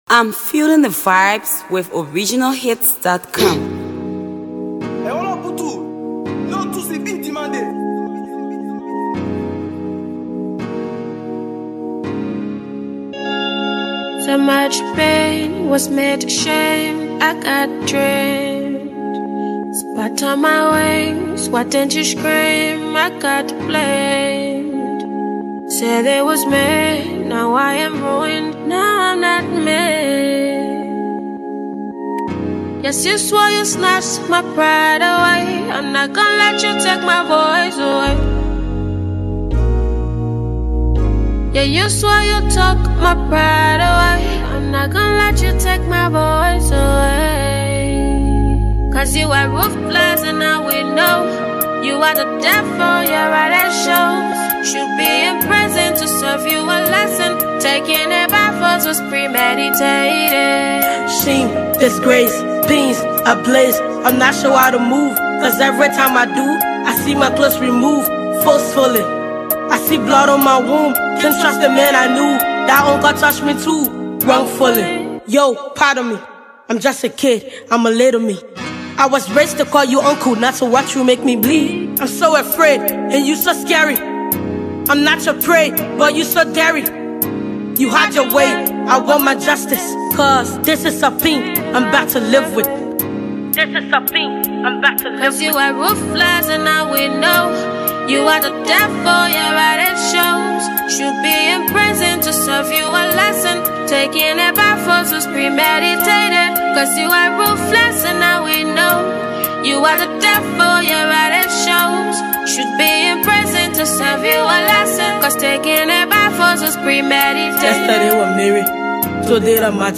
hipco